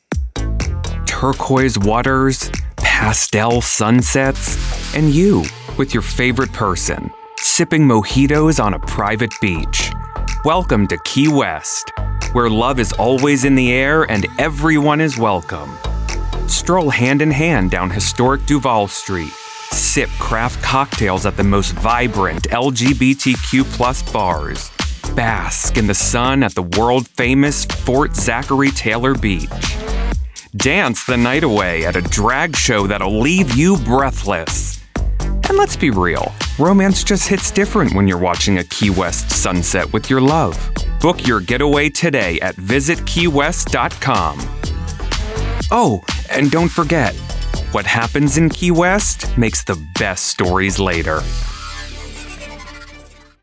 Middle Aged
My reads land with confidence and playfulness.